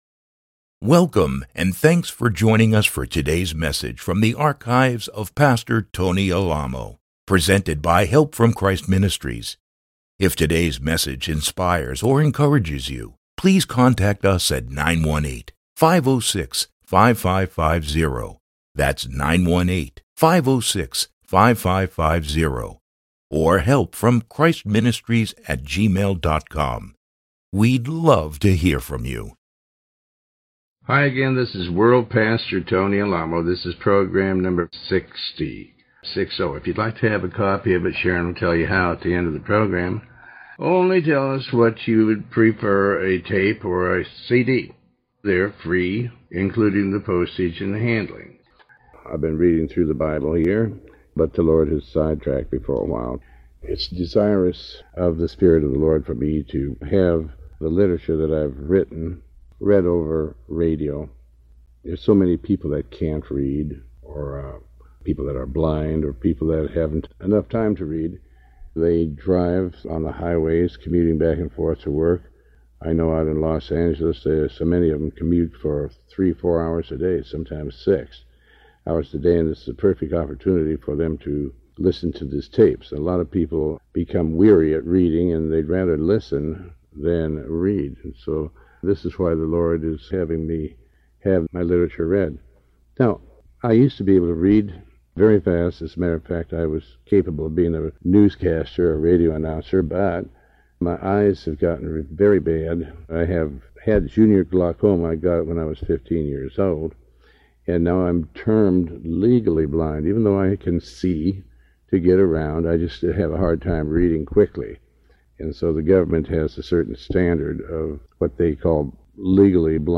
Sermon 60B